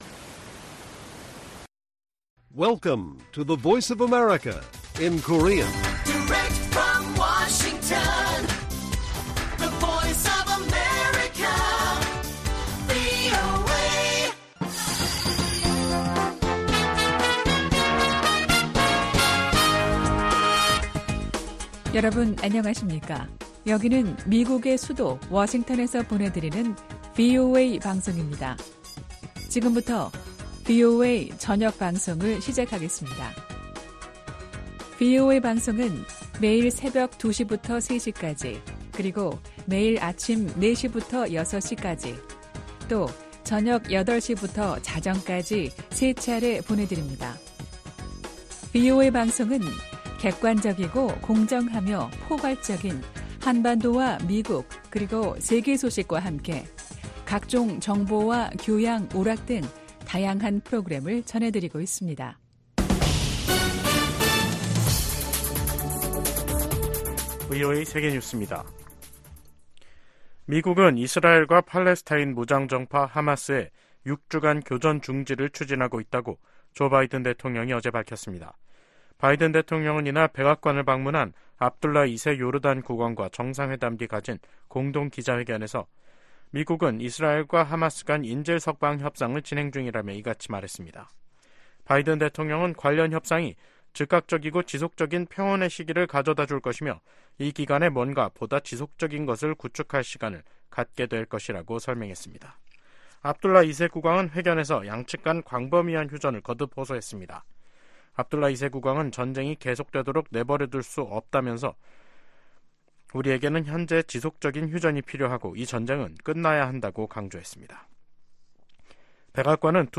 VOA 한국어 간판 뉴스 프로그램 '뉴스 투데이', 2024년 2월 13일 1부 방송입니다. 존 커비 백악관 국가안전보장회의(NSC) 전략소통조정관은 인도태평양 전략 발표 2주년을 맞아 대북 감시를 위한 한반도 주변 역량 강화가 큰 성과라고 평가했습니다. 러시아가 북한의 7차 핵실험 가능성 등을 언급하며 북한 입장을 두둔하고 있습니다. 로버트 켑키 미 국무부 부차관보는 미국·한국·일본이 북한-러시아 군사협력 대응 공조에 전념하고 있다고 밝혔습니다.